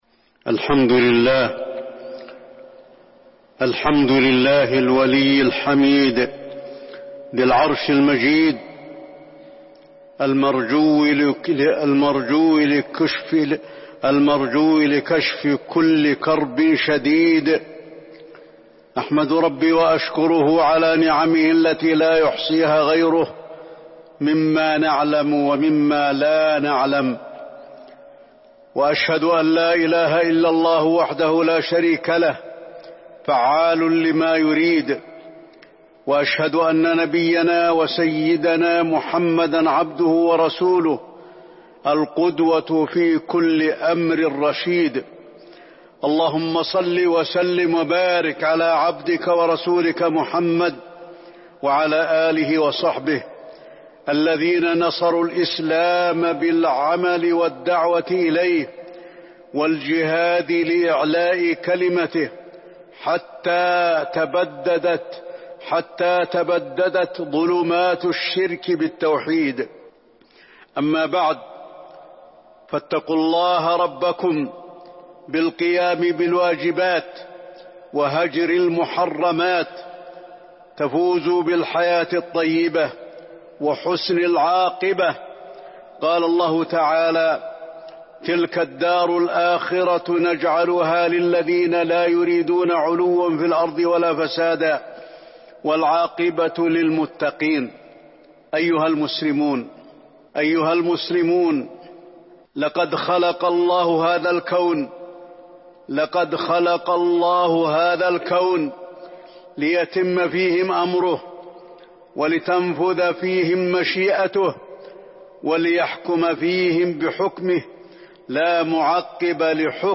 تاريخ النشر ١٢ ذو القعدة ١٤٤١ هـ المكان: المسجد النبوي الشيخ: فضيلة الشيخ د. علي بن عبدالرحمن الحذيفي فضيلة الشيخ د. علي بن عبدالرحمن الحذيفي شكر النعم